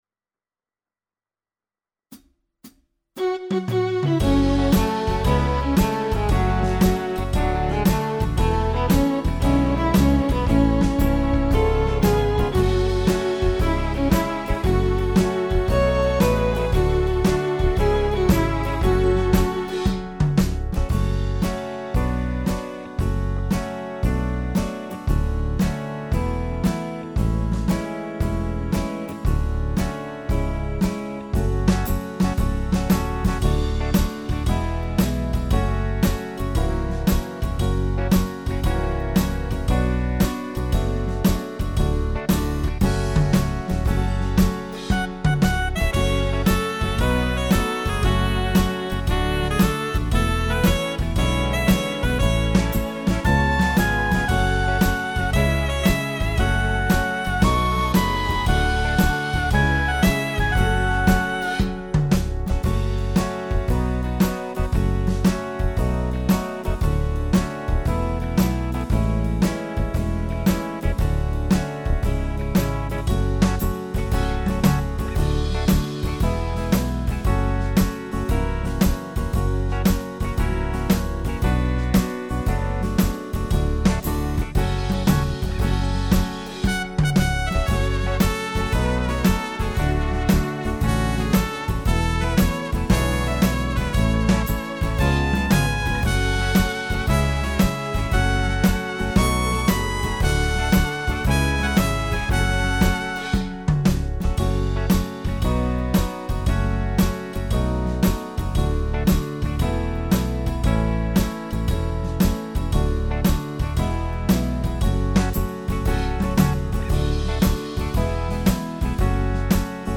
•   Beat  03.